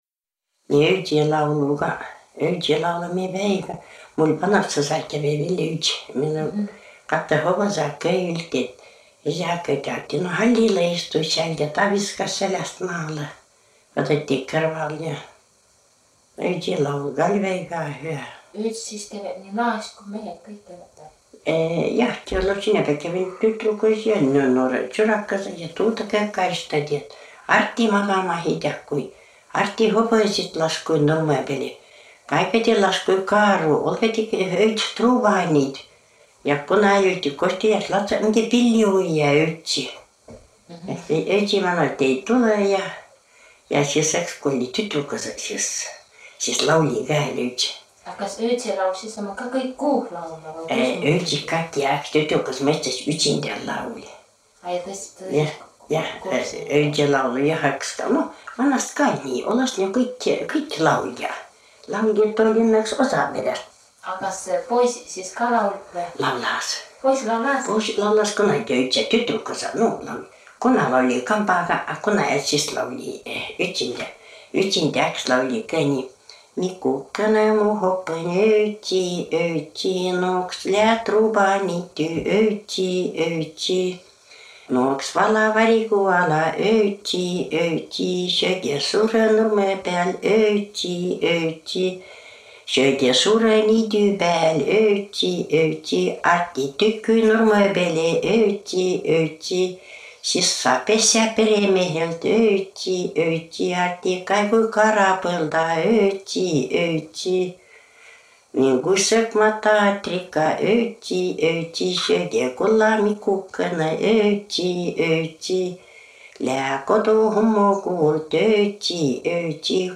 Jutt öütsil käümisest “Mikokõnõ, mu hopõn, öütsi-öütsi!”